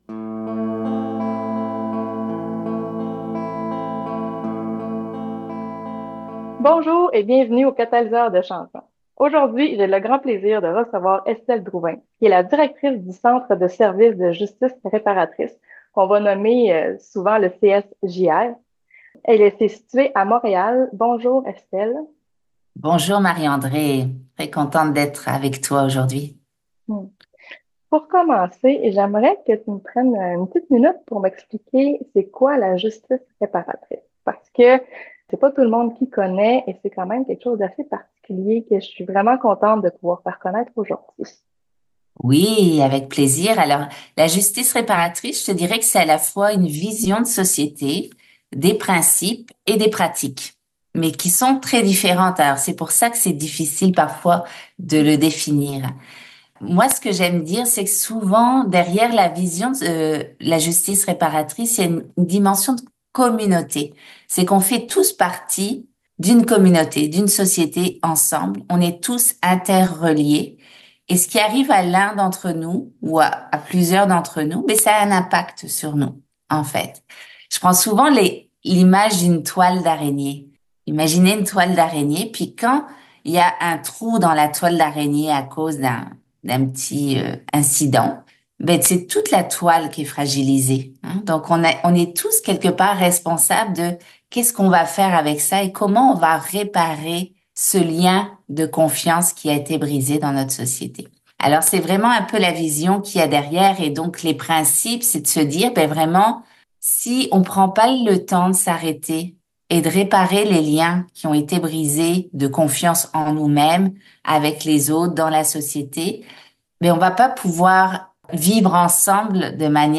Des entrevues avec des personnes qui catalysent le changement et impactent le monde positivement à leur façon !